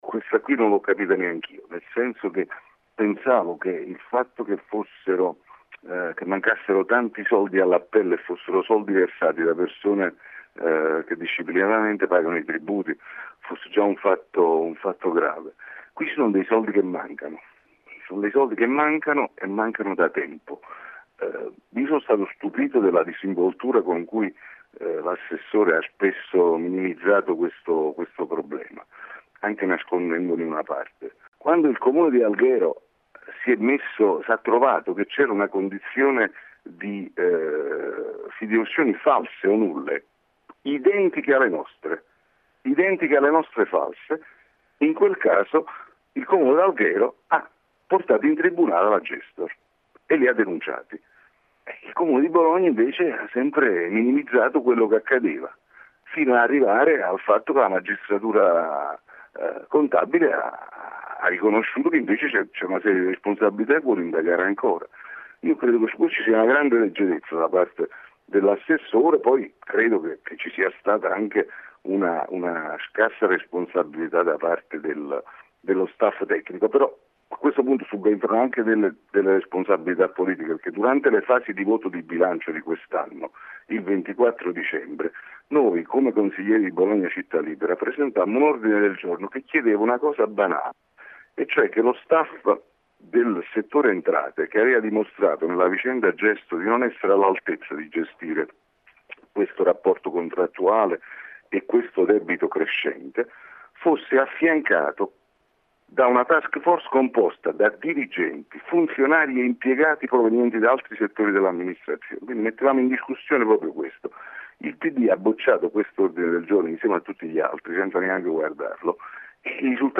Questo è quanto D’Onofrio ha detto ai nostri microfoni.